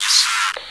radio_yes1.wav